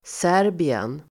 Serbien pronomen, Uttal: [s'är:bien] Definition: ett land i Europa